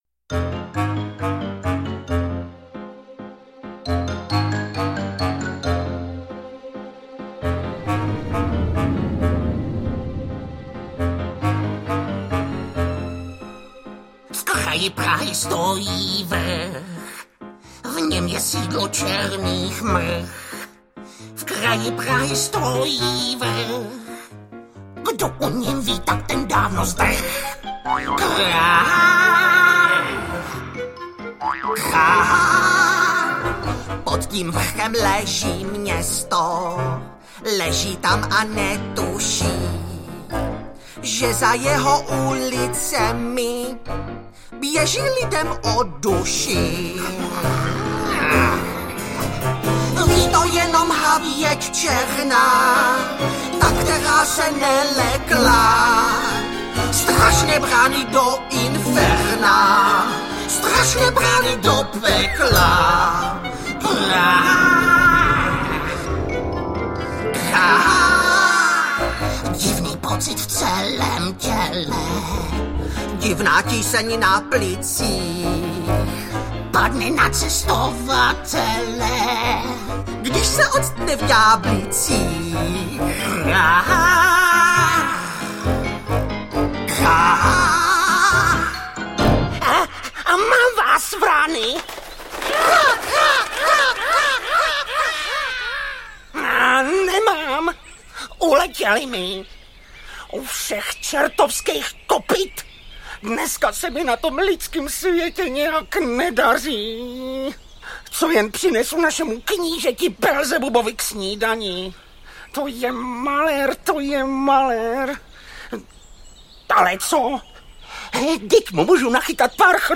Jak s Máničkou šili všichni čerti audiokniha
Ukázka z knihy